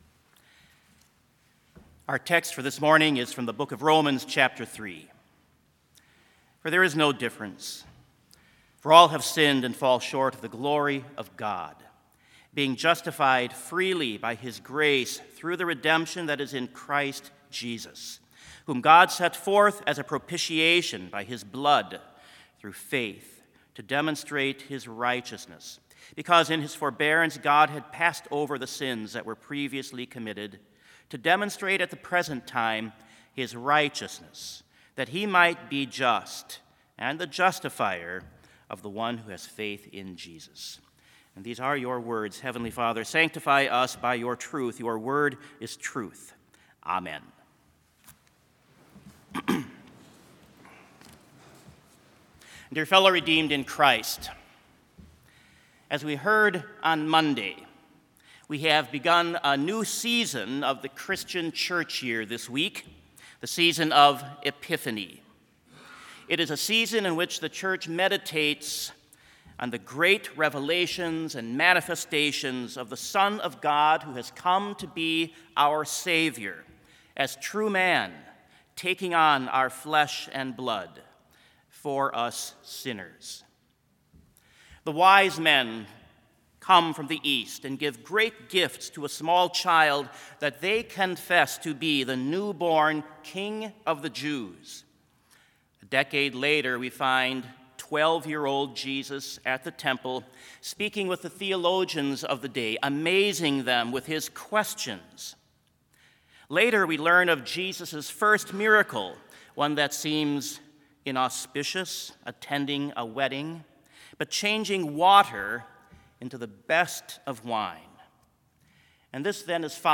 Sermon Only
This Chapel Service was held in Trinity Chapel at Bethany Lutheran College on Wednesday, January 8, 2020, at 10 a.m. Page and hymn numbers are from the Evangelical Lutheran Hymnary.